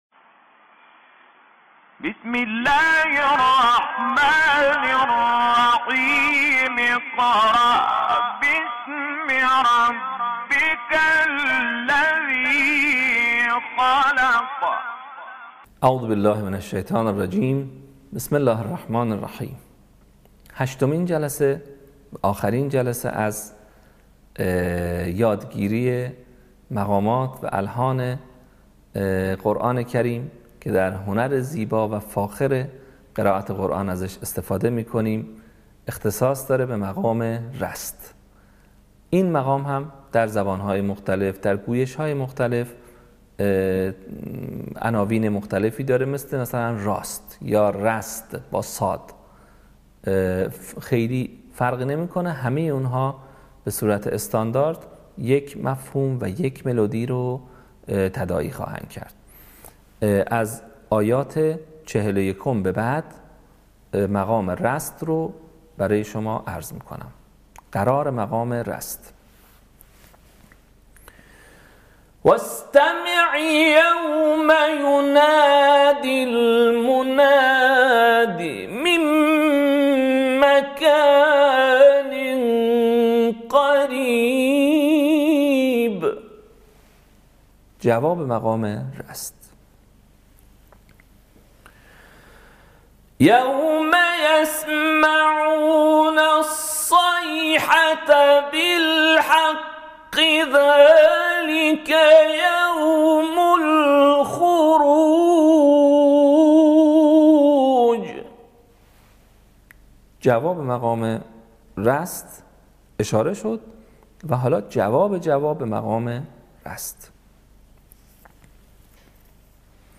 صوت | آموزش مقام رست